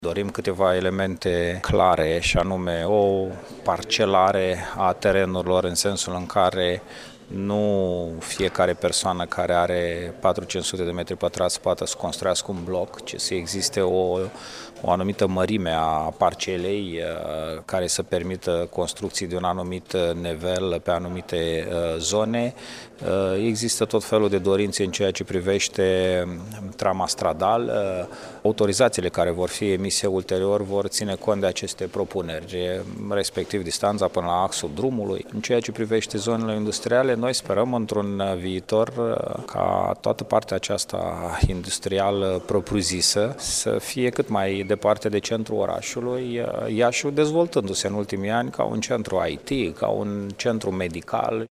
Declaraţia aparţine viceprimarului Iaşului, Radu Botez, şi a fost făcută astăzi, în cadrul unei întâlniri cu oamenii de afaceri.
În schimb, pentru zona centrală a Iaşului sunt în dezbatere trei planuri urbanistice zonale, a mai precizat viceprimarul localităţii, Radu Botez: